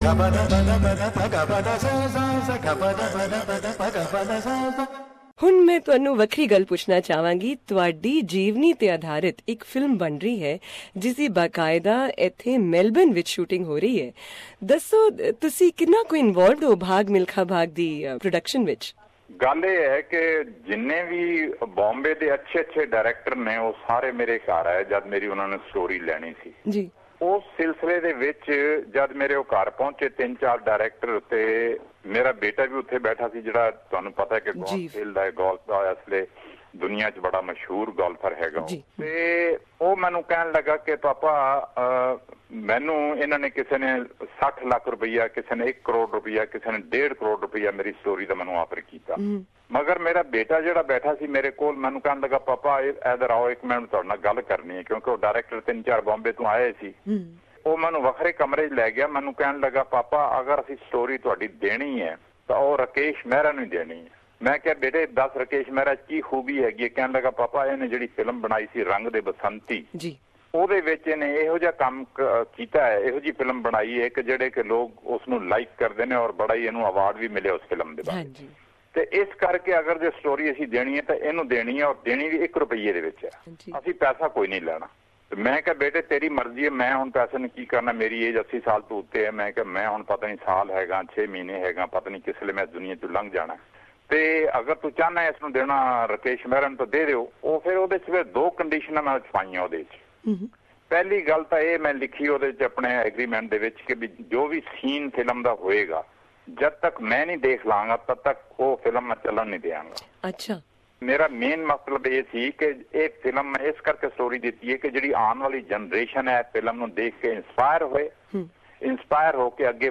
India on Saturday mourned the loss of legendary athlete Milkha Singh, who overcame childhood tragedy to seek Olympic glory after he died aged 91 following a month-long battle with COVID-19. Tune into this interview we had recorded in 2012 with the incomparable 'Flying Sikh' when his biographical film 'Bhaag Milkha Bhaag' was still being filmed in Melbourne (where he participated in the 1956 Olympics).